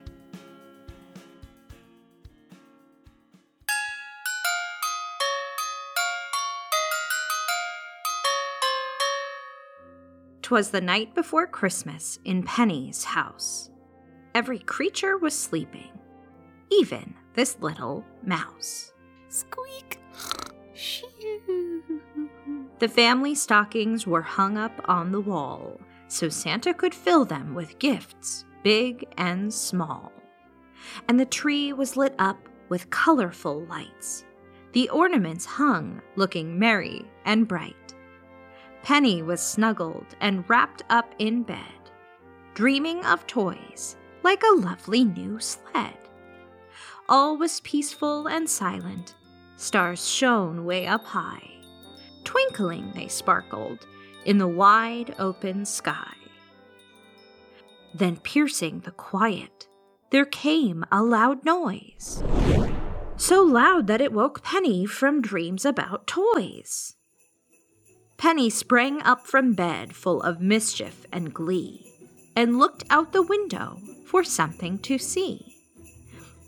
The Night Before Christmas – A Short Rhyming Story – 1 Kid